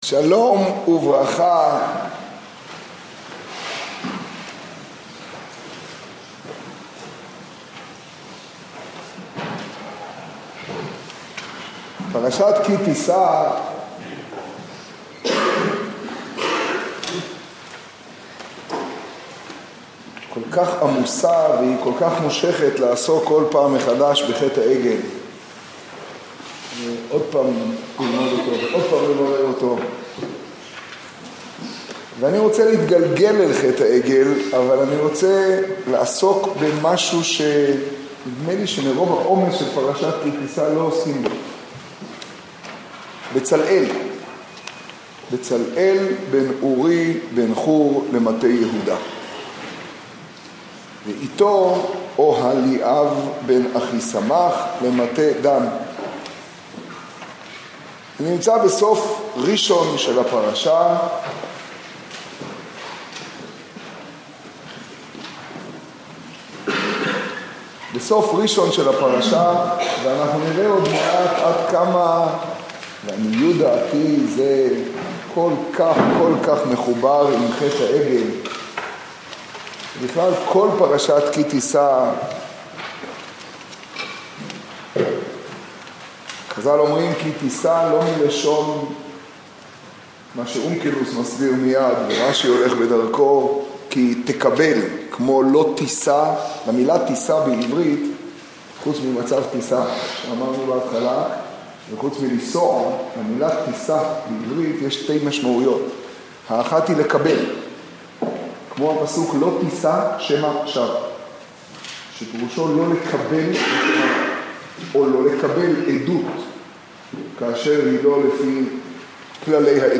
השיעור בירושלים, פרשת כי תשא תשעו.